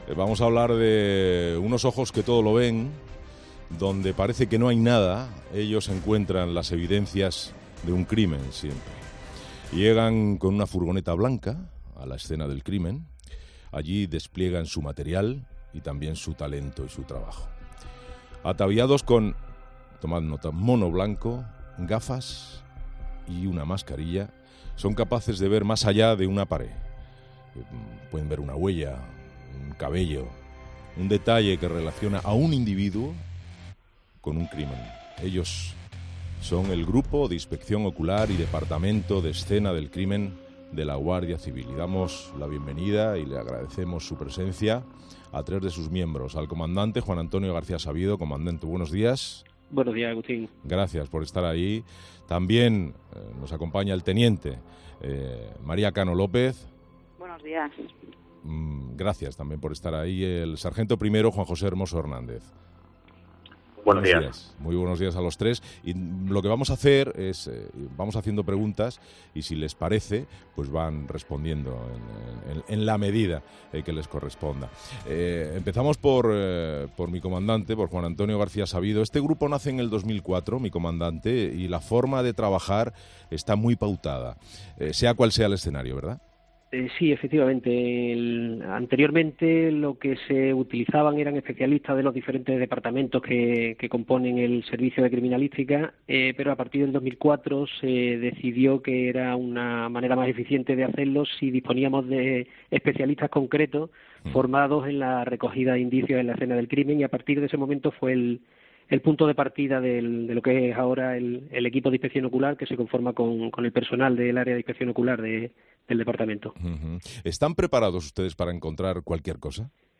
Herrera en COPE